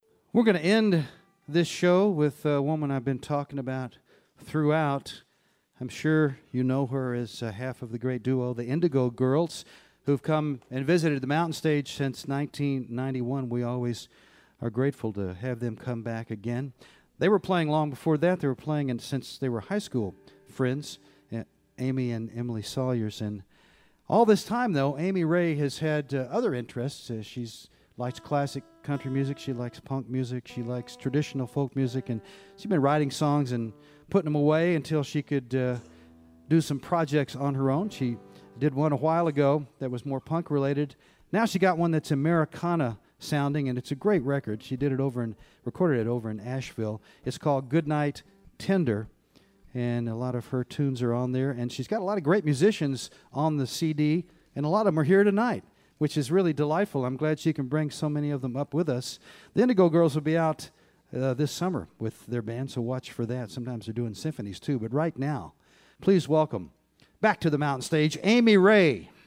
lifeblood: bootlegs: 2015-02-15: mountain stage radio show - virginia thomas law center for performing arts - buckhannon, west virginia (amy ray)
(recording of webcast)